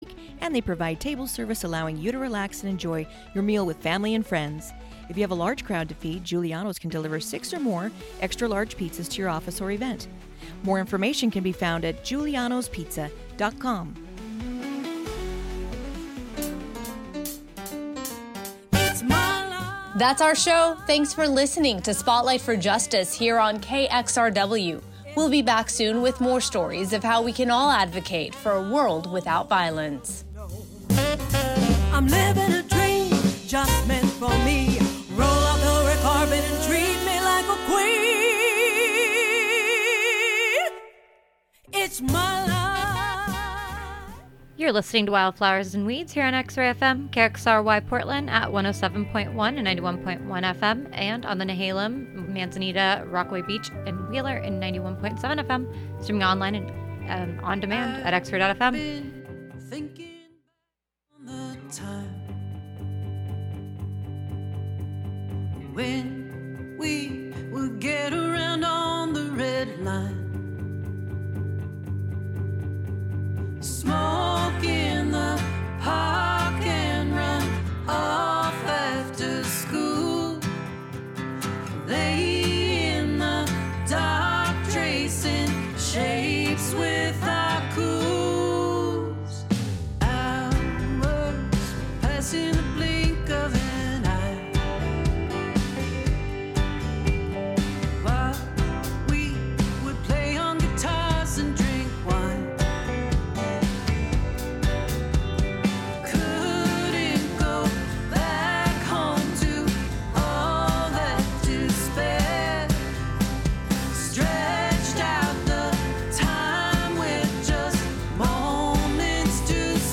A mix of independent & vintage alt, outlaw, cosmic, gothic, red dirt & honky tonk country, roots, blue grass, folk, americana, rock and roll & even desert psych and stadium hits (when acceptable). We feature a strong emphasis on female artists. We tie together new independent artists and new releases with classics that inspired.